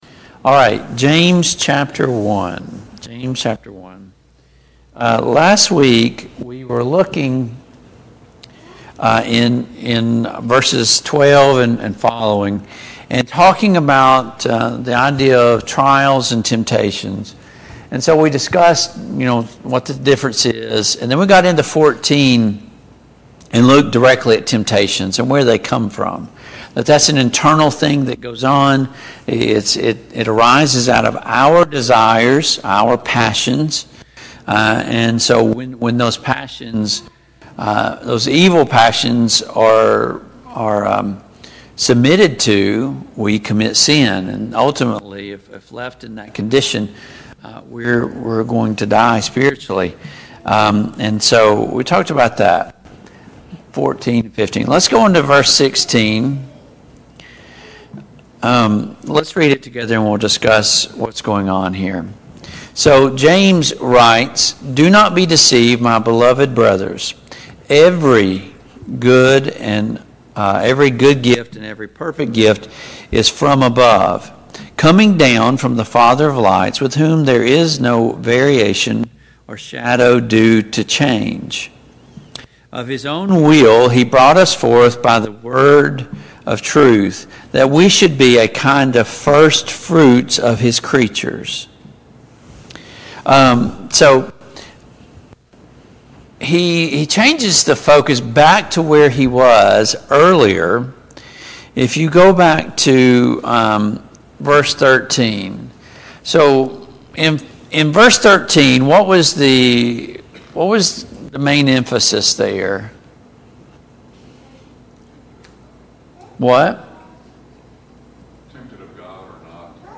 Study of James and 1&2 Peter Series: Study of James and 1 Peter and 2 Peter , Study of James and 1&2 Peter Passage: James 1:13-20 Service Type: Family Bible Hour « Forgiveness is Powerful!